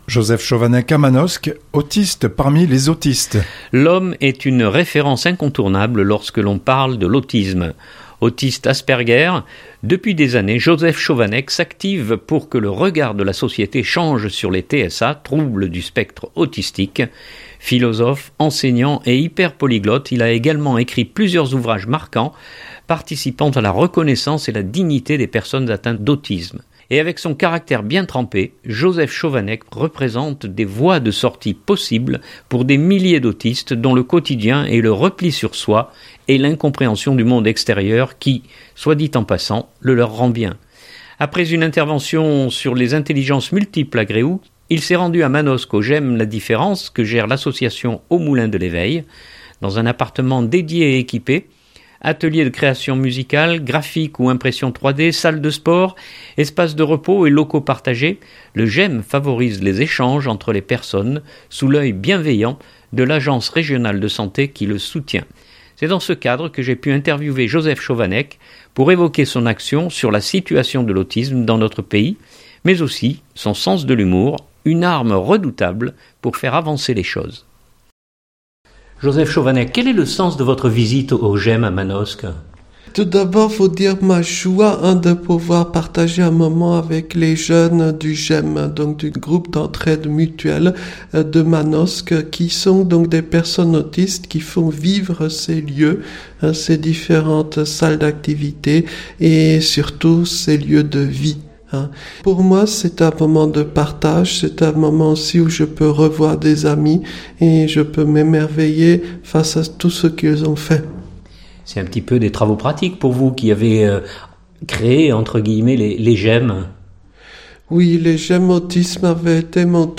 C’est dans ce cadre que j’ai pu interviewer Josef Schovanec pour évoquer son action sur la situation de l’autisme dans notre pays mais aussi son sens de l’humour, une arme redoutable pour faire avancer les choses…